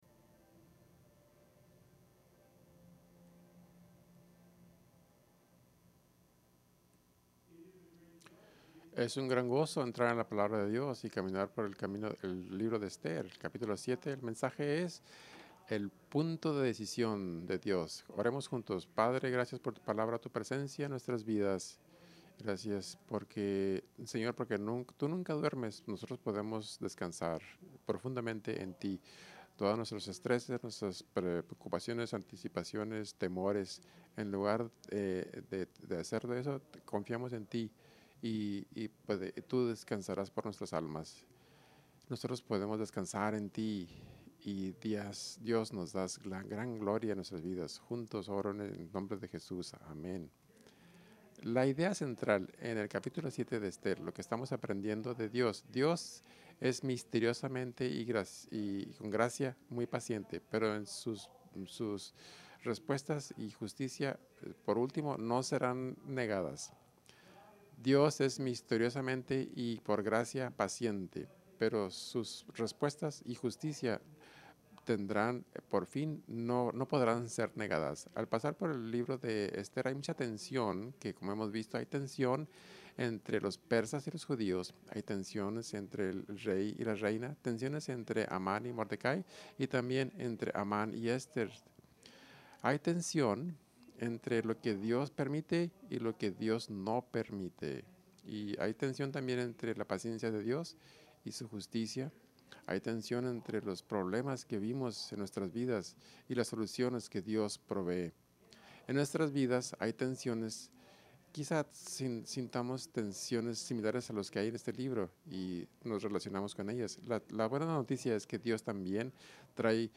Sermon series in the book of Esther